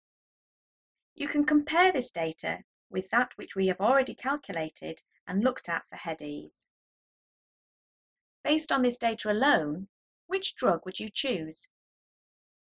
Narration audio (MP4)